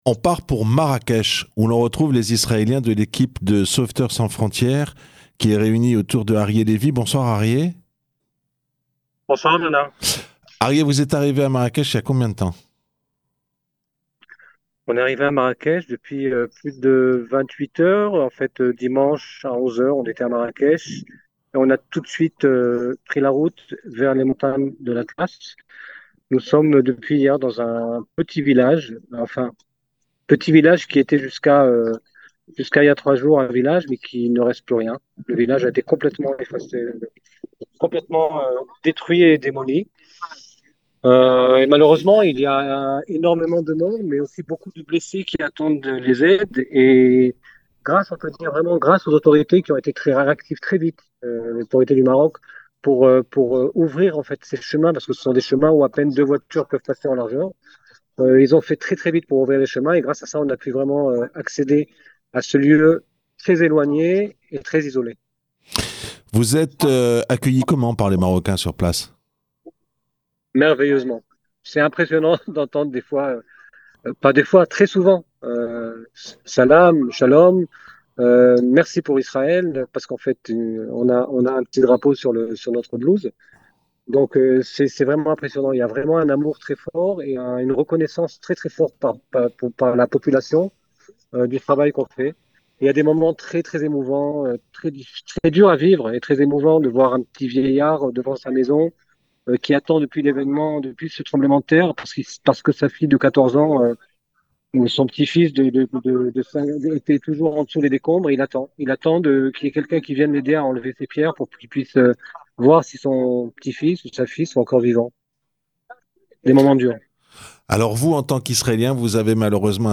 Avec les secouristes israéliens en direct de Marrakech